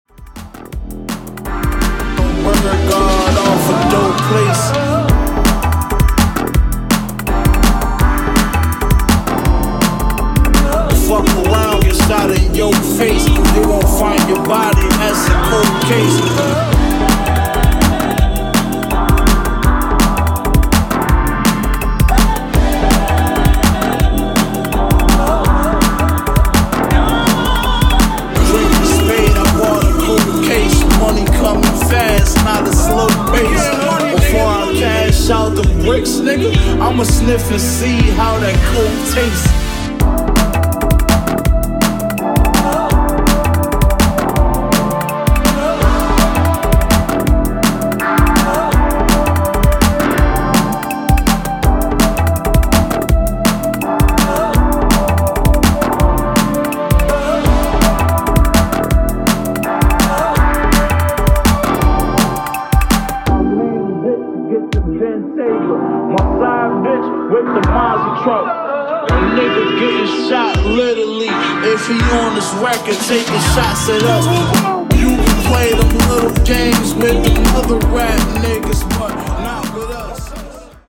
hip hop / d&b roller